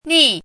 怎么读
逆 [nì]
ni4.mp3